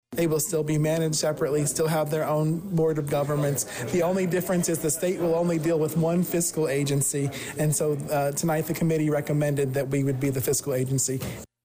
Danville’s Mayor Rickey Williams, Jr; who attended the committee meeting, is glad to get it worked out.  The city will continue running their fixed routes; and the county, through CRIS, will continue providing needed door-to-door and senior and disabled transportation, for those within the city and the county.